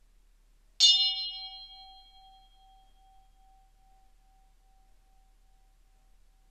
Bell 05
bell bing brass ding sound effect free sound royalty free Sound Effects